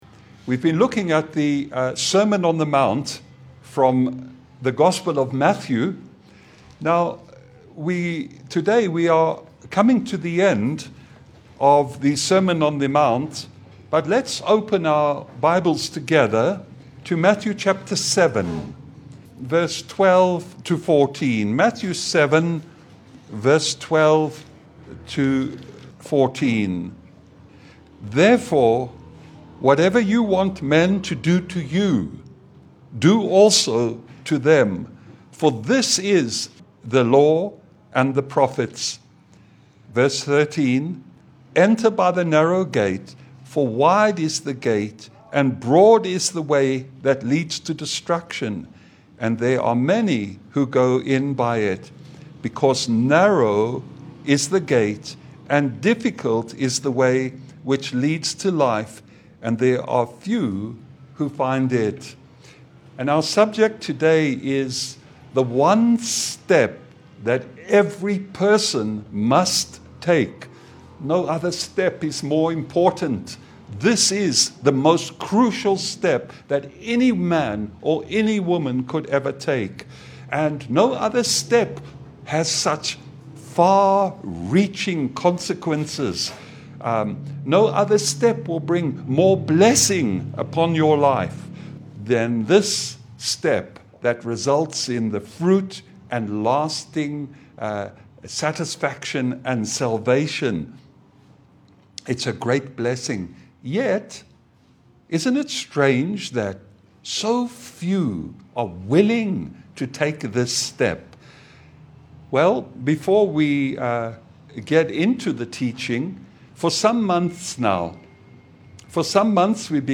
Service Type: Sunday Bible fellowship